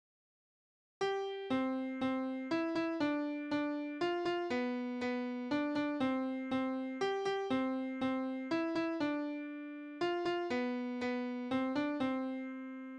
Tonart: C-Dur
Taktart: 3/4
Tonumfang: kleine Sexte